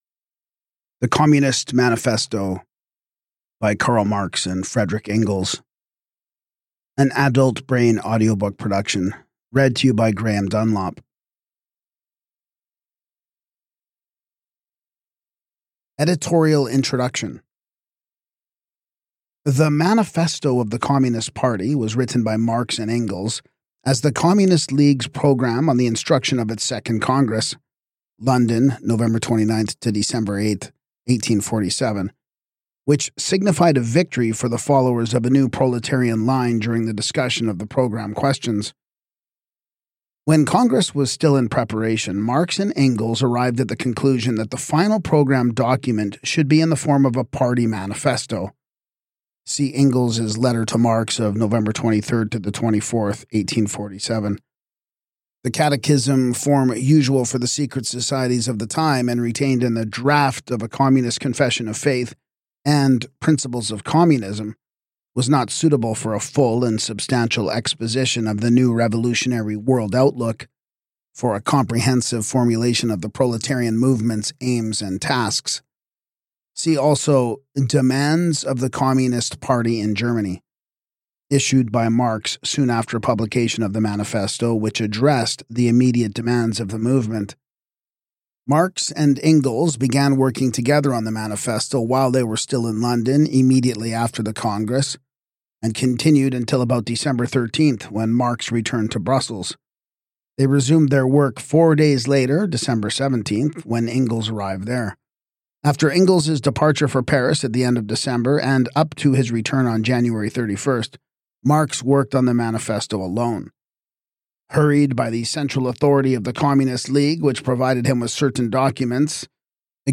Podcast (audiobooks):